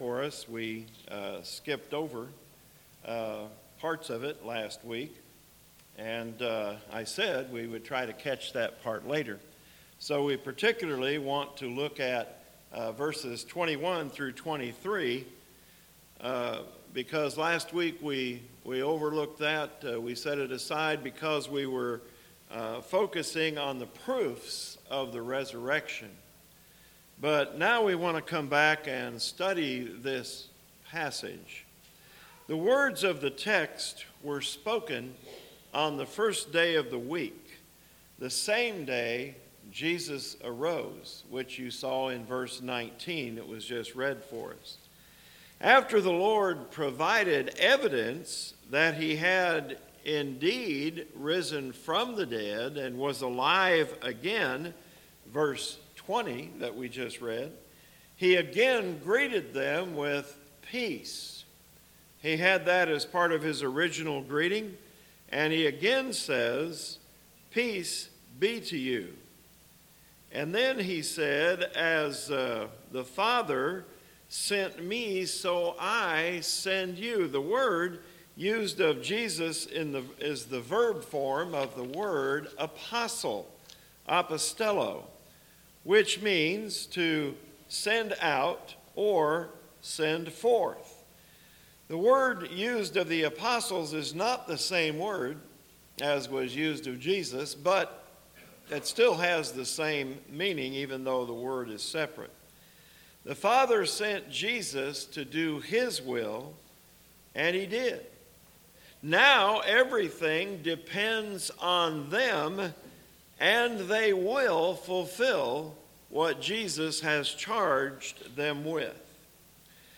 Recorded audio gospel sermons from the pulpit of South Seminole church of Christ.